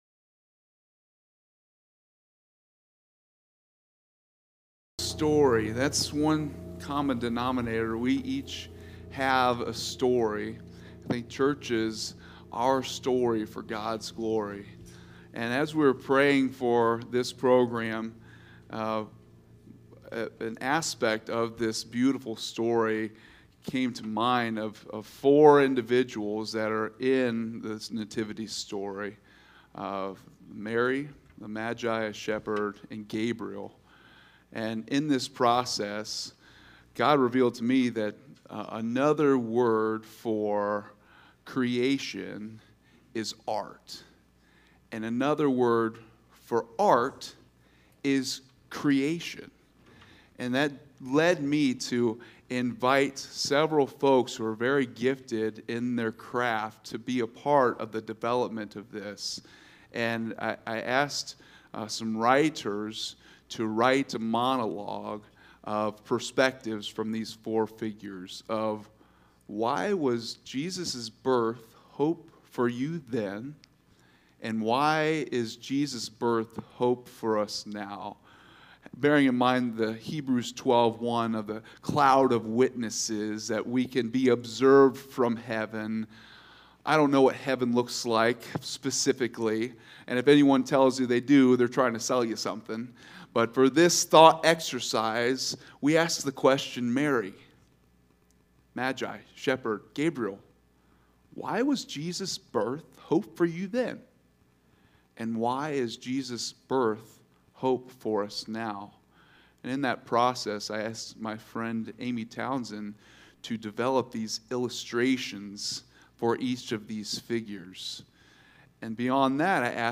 This week is our Annual Christmas Program, which will feature a variety of drama, wonderful Christmas music (including a couple numbers from our kids
Watch the entire Worship Service